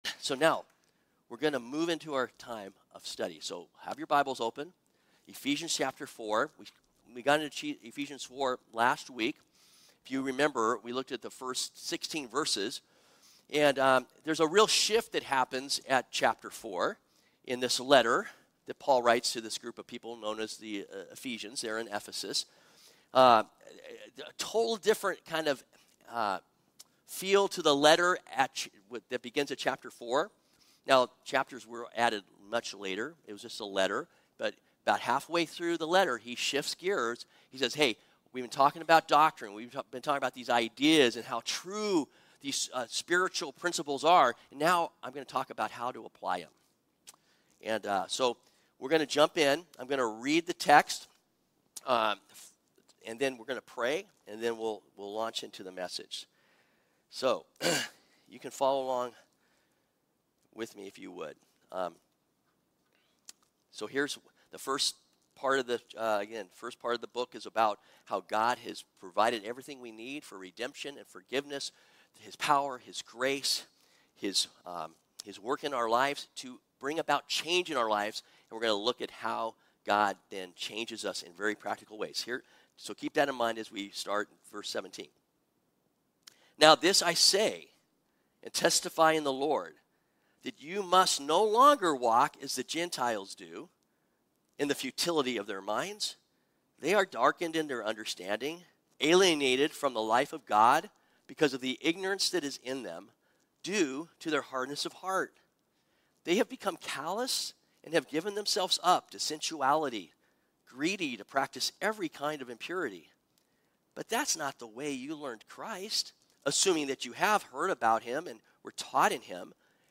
Our sermon series continues with part 11, God's New Family from the book of Ephesians.